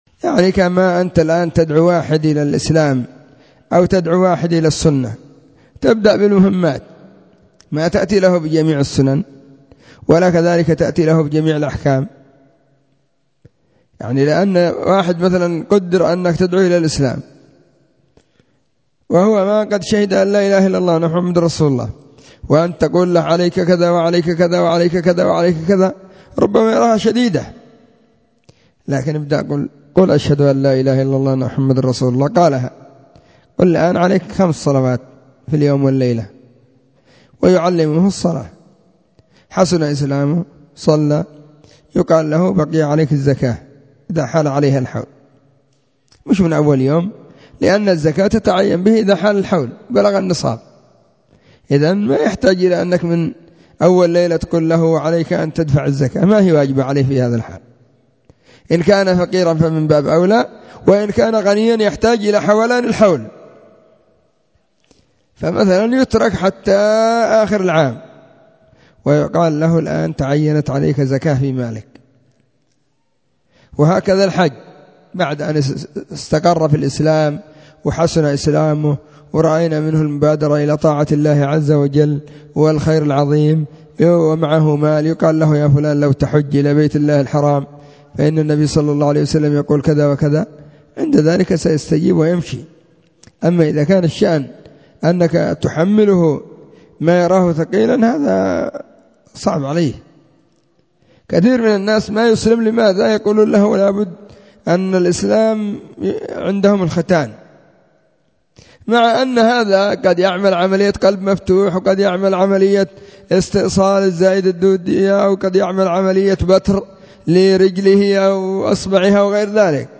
📢 مسجد الصحابة بالغيضة, المهرة، اليمن حرسها الله.